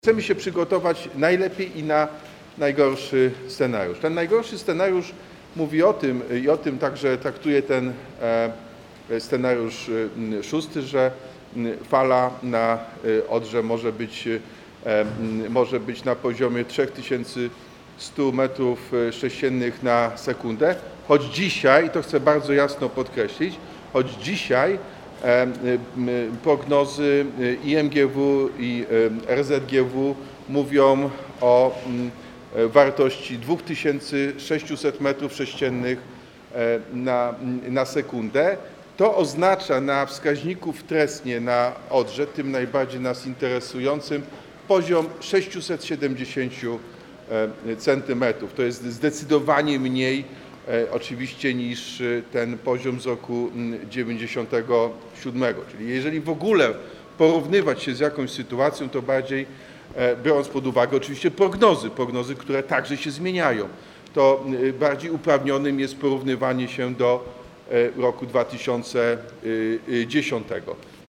Wrocław ogłosił alarm przeciwpowodziowy, co jest wynikiem potencjalnych zagrożeń związanych z wysokim stanem wód na Odrze. Mimo, że prognozy wskazują na opadanie wody i stabilizację sytuacji, miasto przygotowuje się na najgorszy scenariusz, aby uniknąć możliwych katastrofalnych skutków – mówi prezydent Jacek Sutryk.